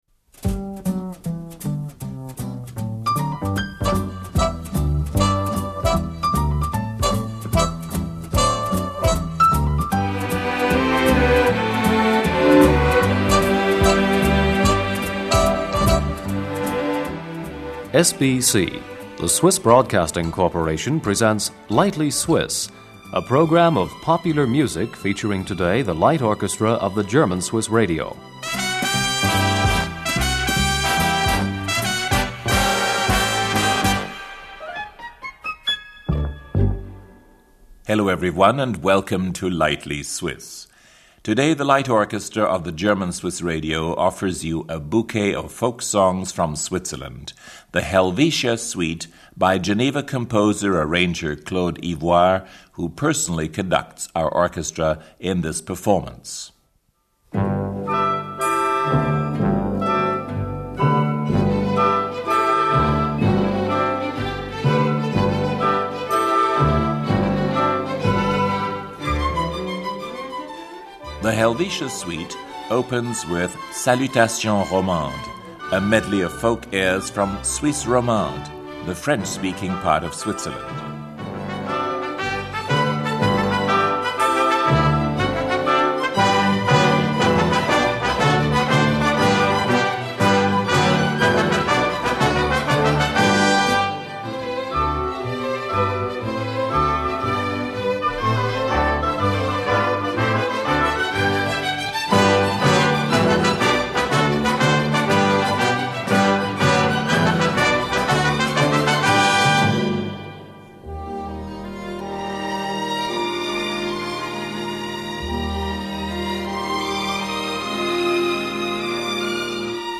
French-Swiss folk medley
German-Swiss folk medley
Italian-Swiss folk medley